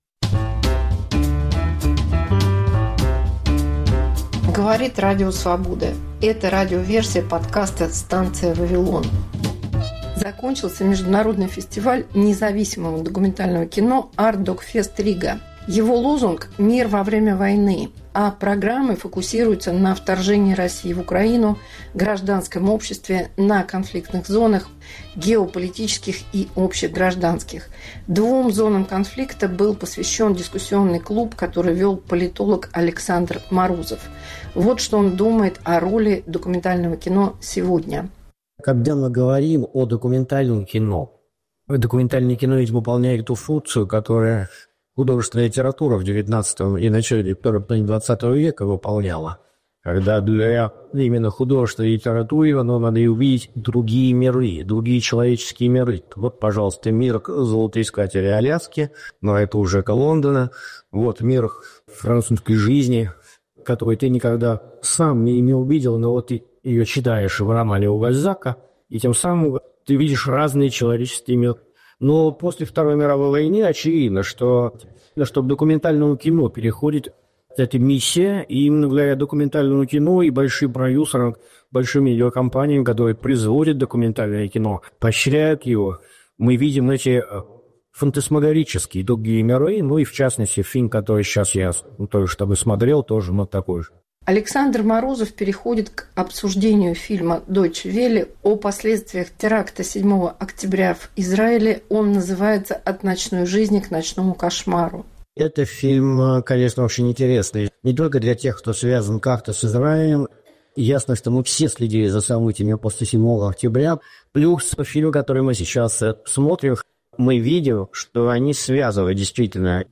Дискуссия на Artdocfest/Riga 2025 Повтор эфира от 09 марта 2025 года.